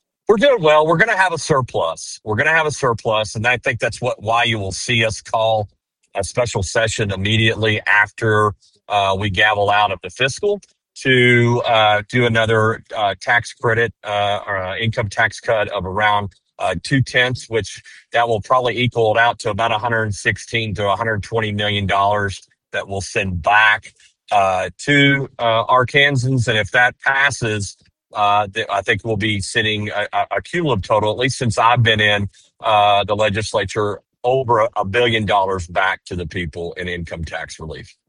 KTLO, Classic Hits and the Boot News spoke with Rep. Painter who says the House passed a resolution to raise the credit by $75, increasing it from $600 to $675.